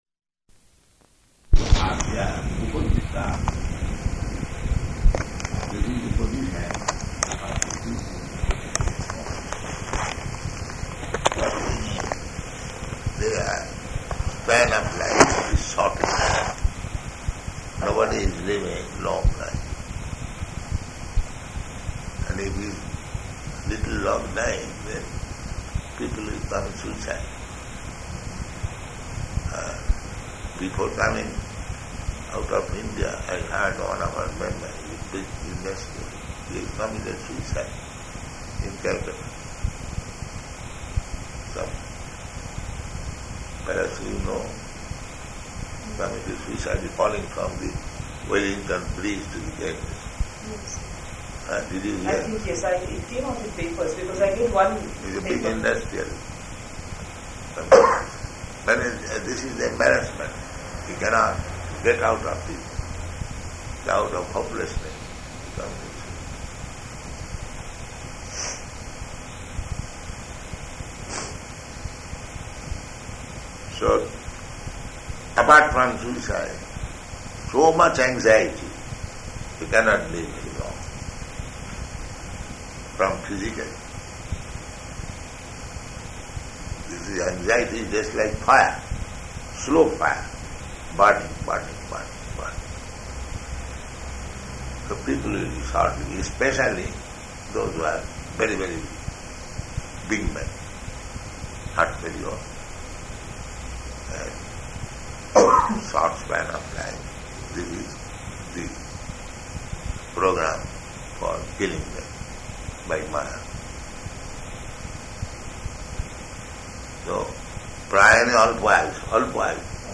Room Conversation
Room Conversation --:-- --:-- Type: Conversation Dated: March 15th 1975 Location: Tehran Audio file: 750315R1.TEH.mp3 Prabhupāda: ...manda-bhāgyā hy upadrutāḥ [ SB 1.1.10 ].